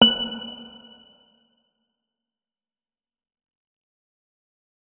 46265b6fcc Divergent / mods / Hideout Furniture / gamedata / sounds / interface / keyboard / guitar / notes-66.ogg 42 KiB (Stored with Git LFS) Raw History